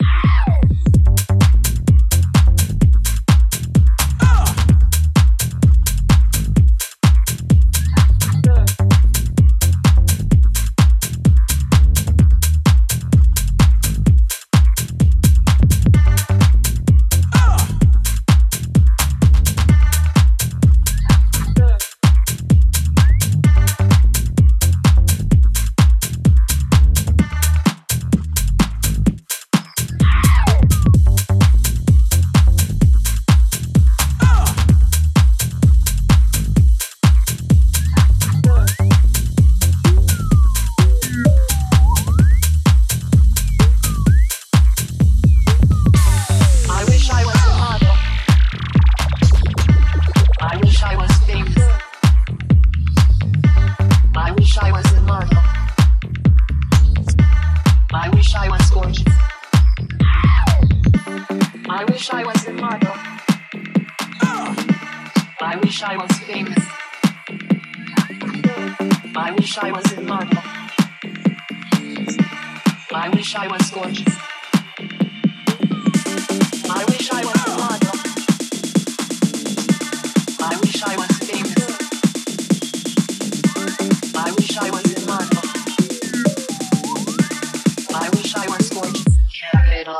ジャンル(スタイル) HOUSE / MINIMAL / TECH HOUSE